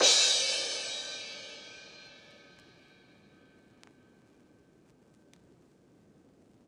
CYCdh_VinylK4-Crash01.wav